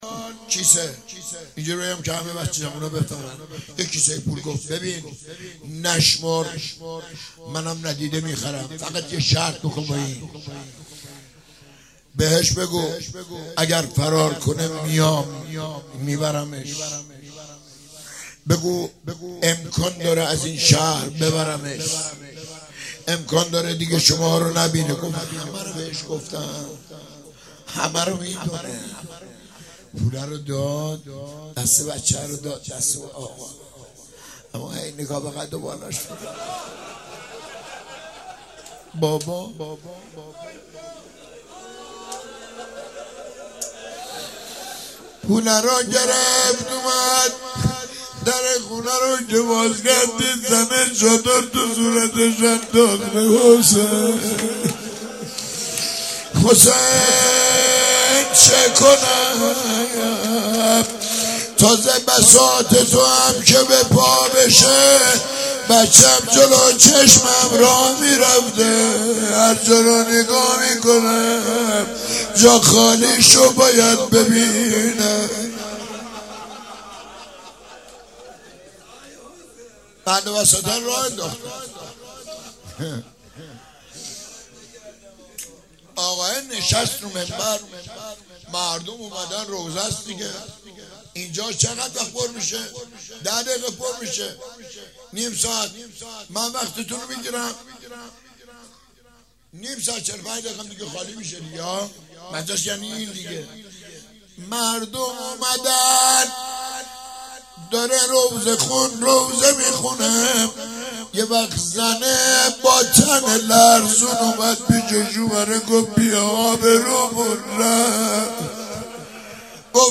روز هفتم محرم95_روضه _بخش دوم_